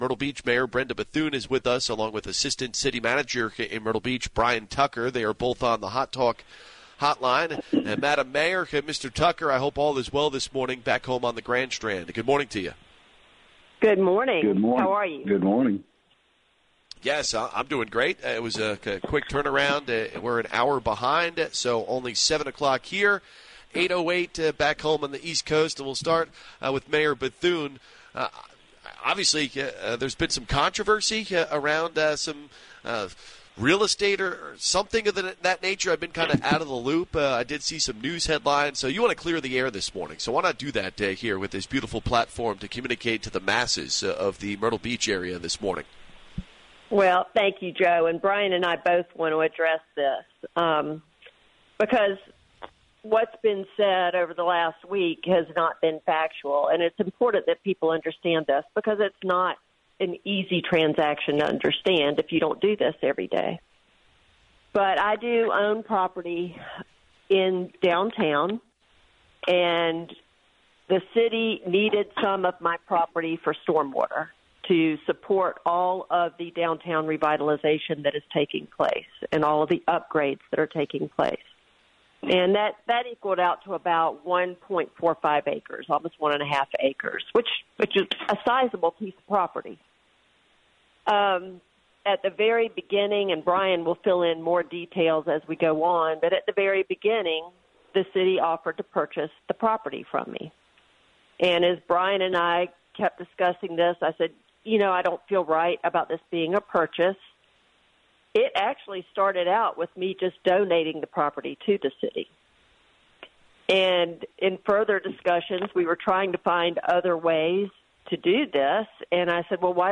The Myrtle Beach Mayor called in on the Hot Talk Hotline to address recent issues in Myrtle Beach, including the Arts and Entertainment district development, the Myrtle Beach Pelicans, and Ocean Blvd safety.
mayor-wrnn.mp3